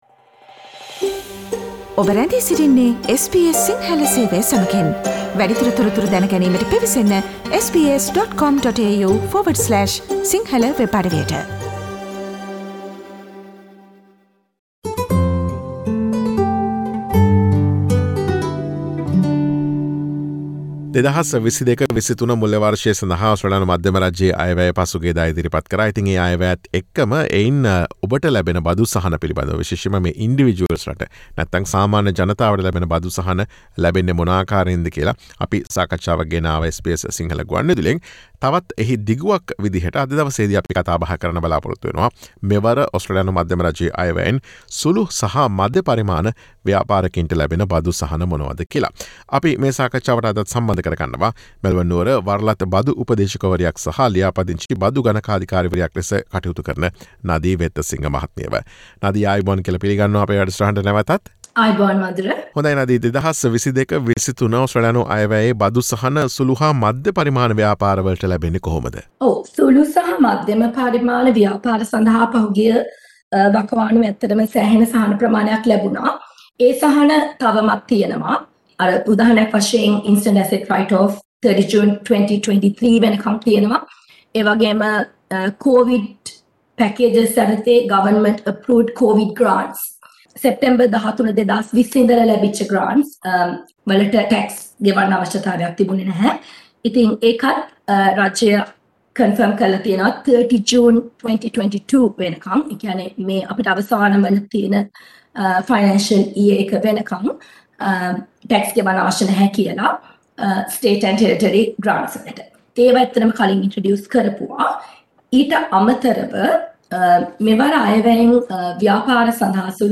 මෙවර ඔස්ට්‍රේලියානු අයවැයෙන් ලබාදුන් බදු සහන ව්‍යාපාරවලට ලැබෙන අයුරු පිළිබඳ SBS සිංහල ගුවන් විදුලිය සිදුකළ සාකච්ඡාවට සවන් දෙන්න.